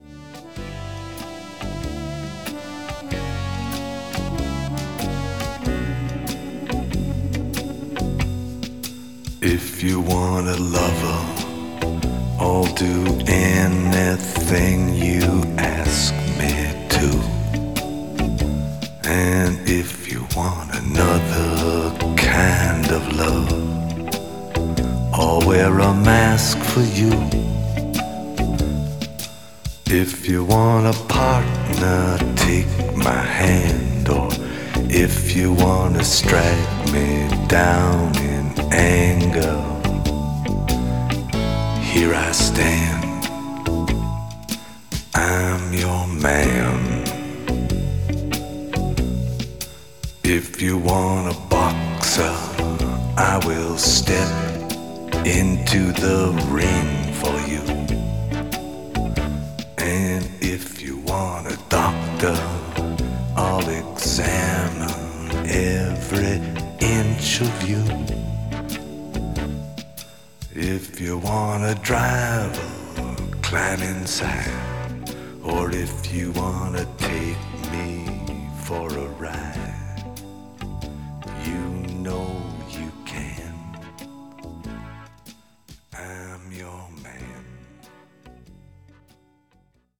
that brilliant songwriter and owner of a most unusual voice.